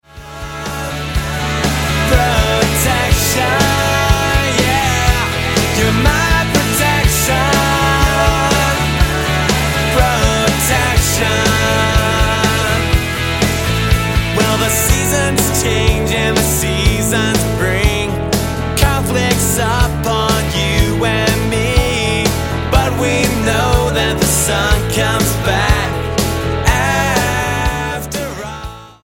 American pop team
Style: Pop